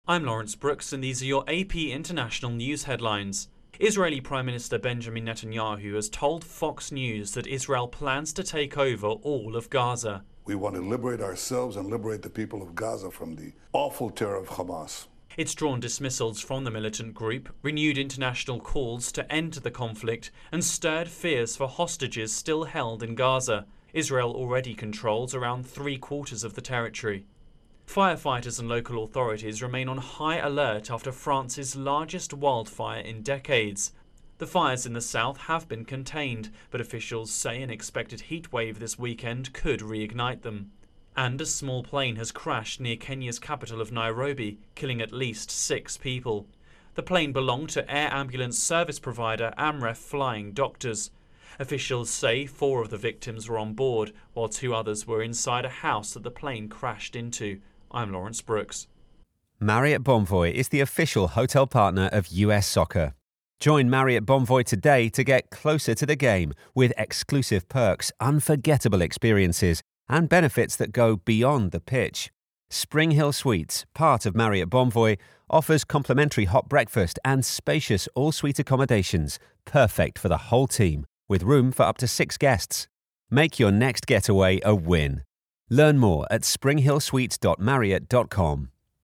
The latest international news headlines
AP correspondent